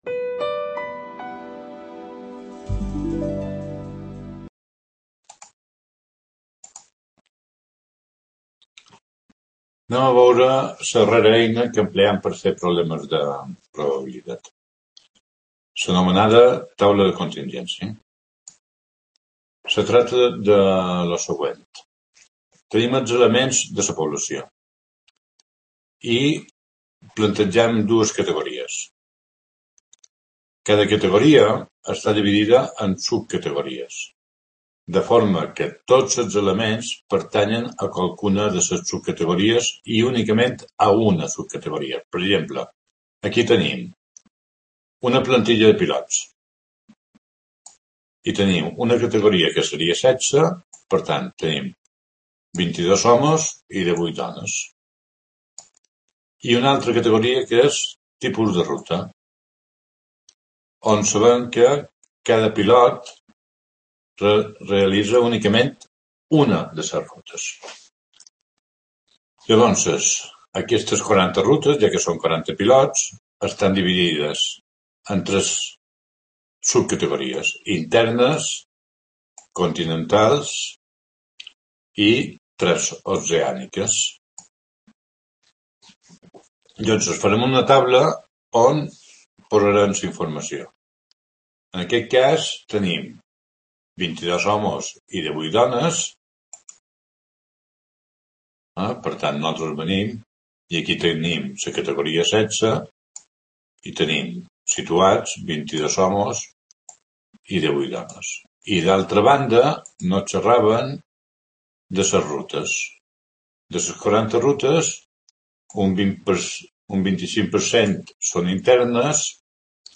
Explicacions
Video Clase